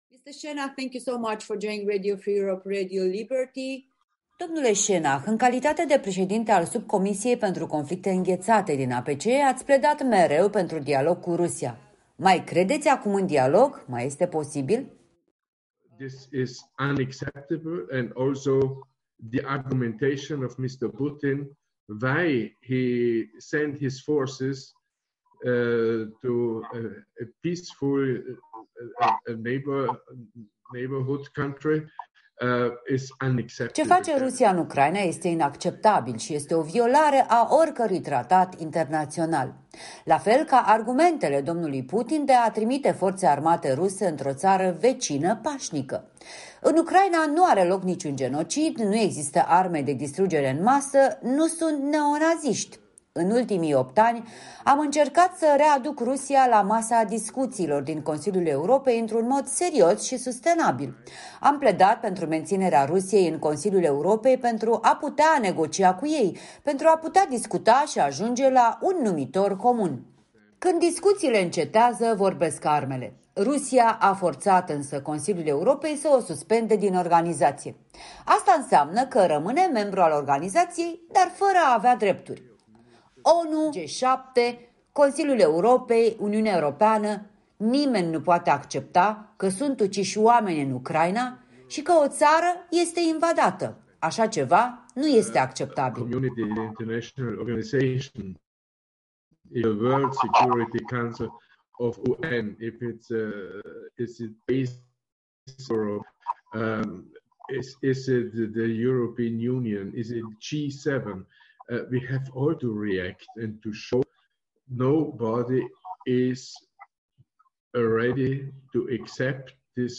Stefan Schennach, președinte al Subcomisiei pentru Conflicte înghețate, APCE